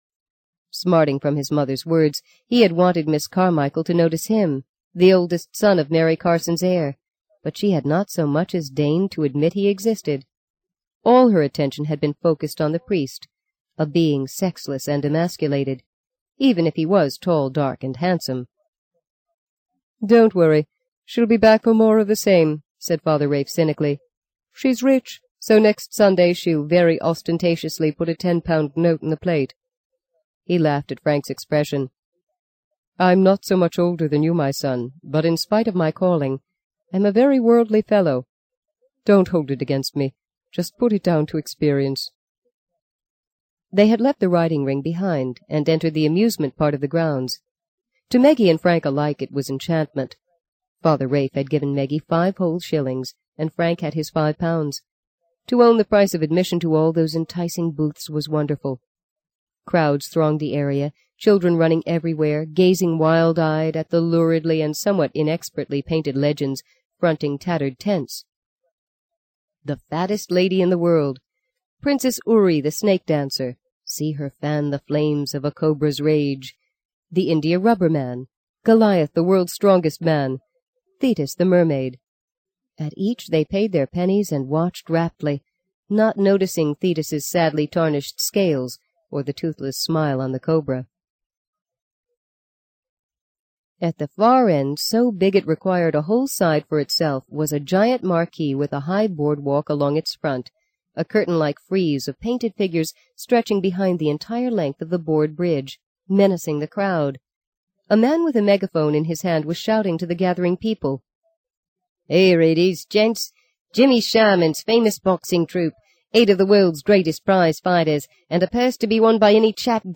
在线英语听力室【荆棘鸟】第五章 04的听力文件下载,荆棘鸟—双语有声读物—听力教程—英语听力—在线英语听力室